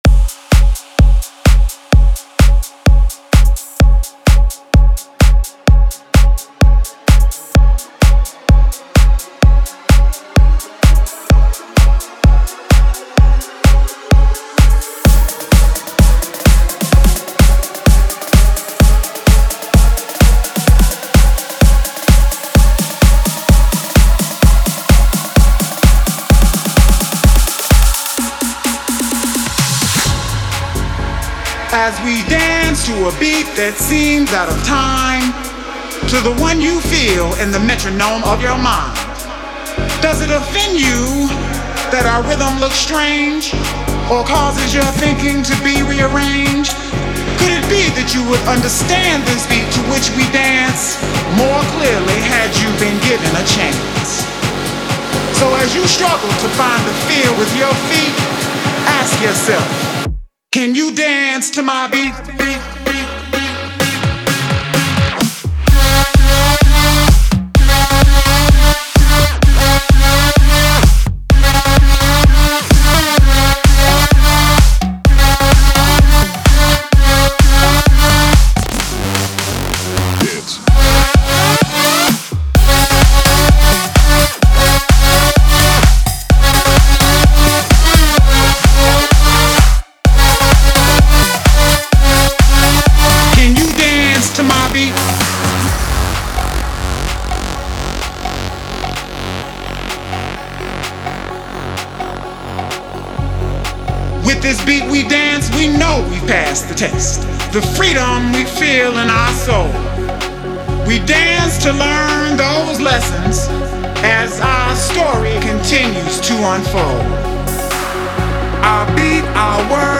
Style: Electro House